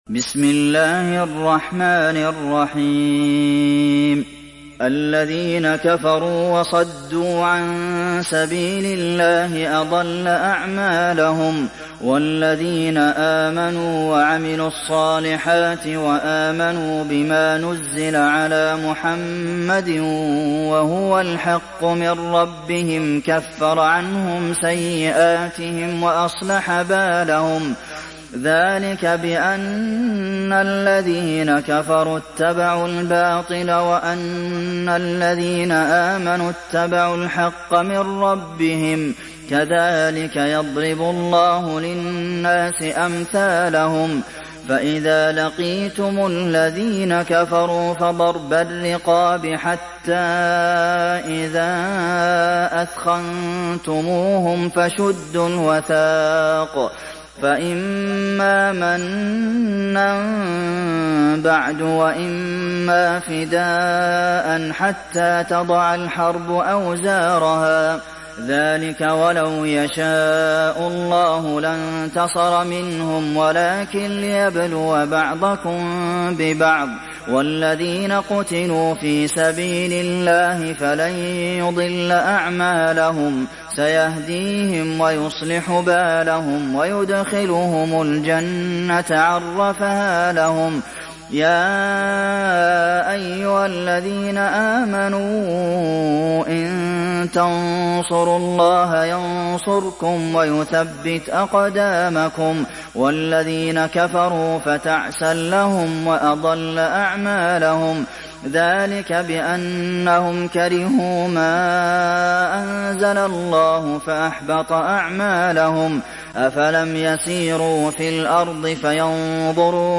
Sourate Muhammad mp3 Télécharger Abdulmohsen Al Qasim (Riwayat Hafs)